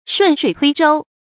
注音：ㄕㄨㄣˋ ㄕㄨㄟˇ ㄊㄨㄟ ㄓㄡ
順水推舟的讀法